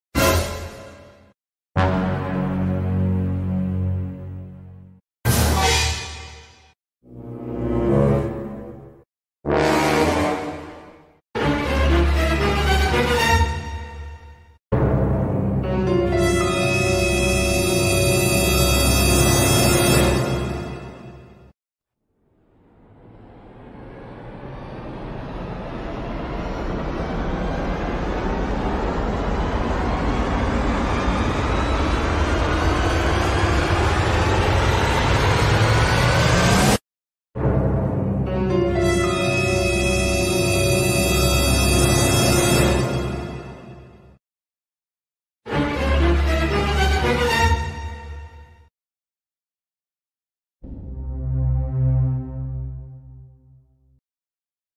Suspense Sound Effect Pack Sound Effects Free Download